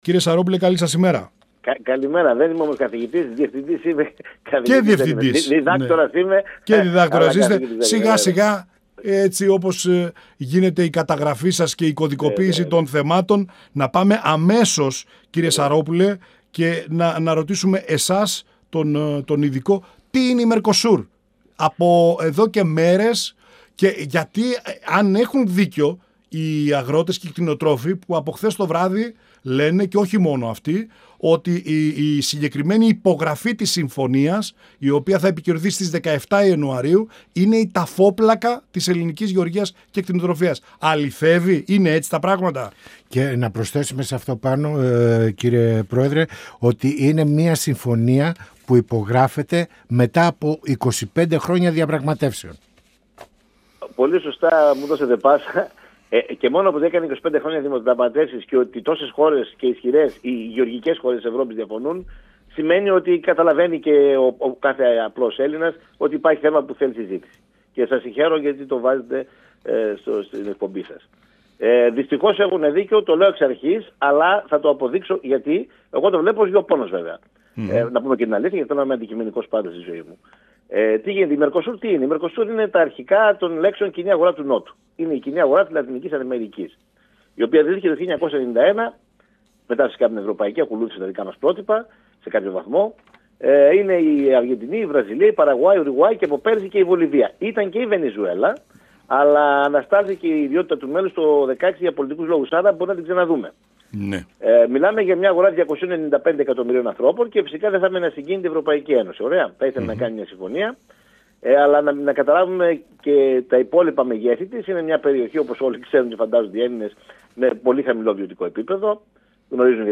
μιλώντας στην εκπομπή «Πανόραμα Επικαιρότητας» του 102FM της ΕΡΤ3.
Πανοραμα Επικαιροτητας Συνεντεύξεις